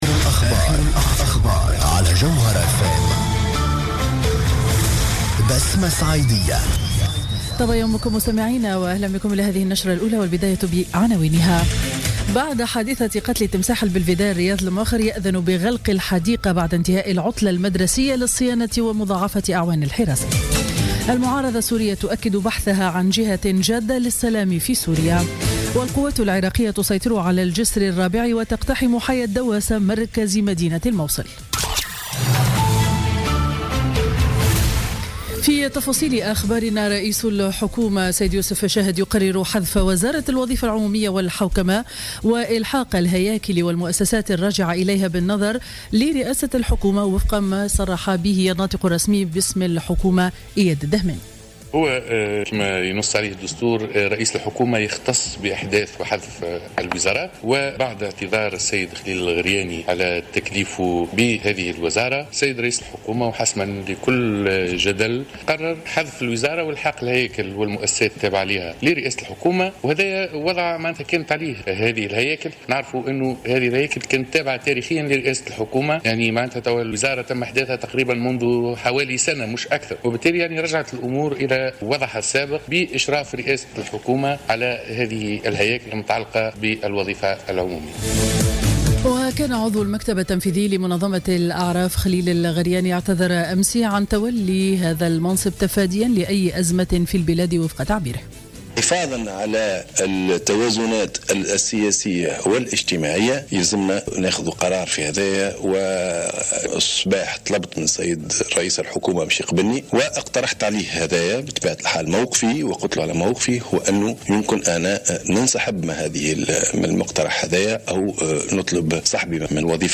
نشرة أخبار السابعة صباحا ليوم الجمعة 3 مارس 2019